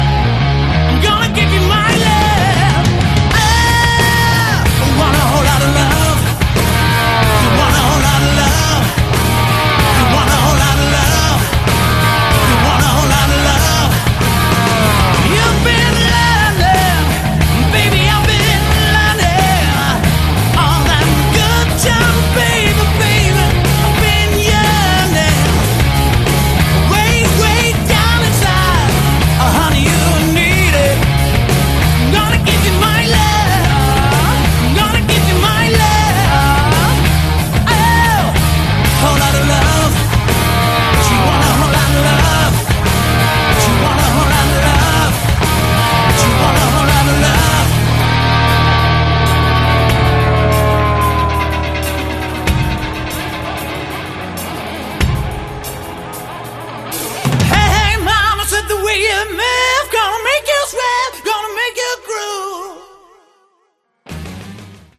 Category: Hard Rock
vocals
guitar
bass
drums